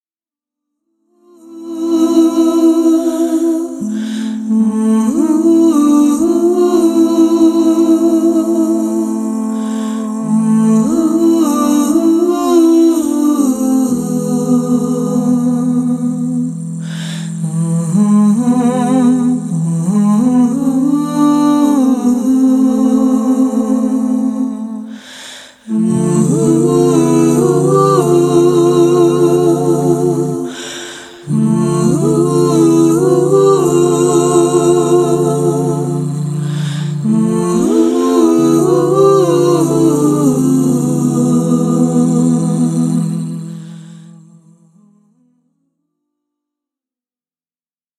version instrumentale